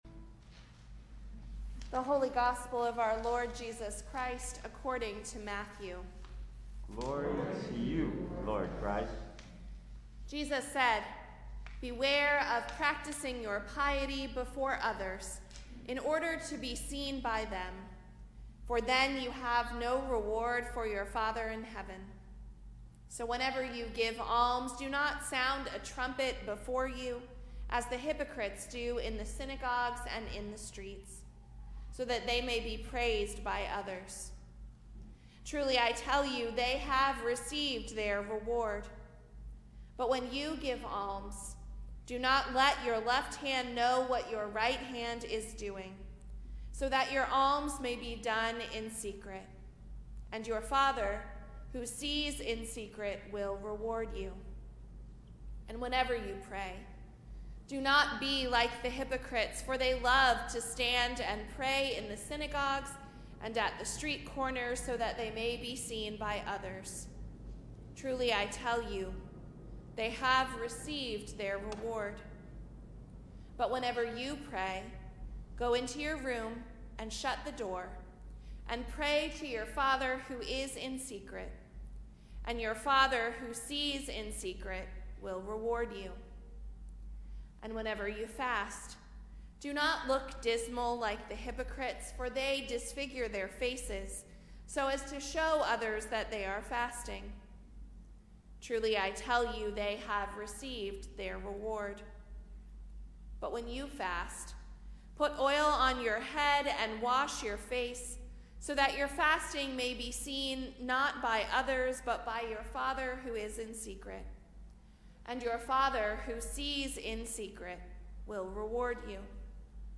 Gospel & Sermon, March 2, 2022, Ash Wednesday - St. Andrew's Episcopal Church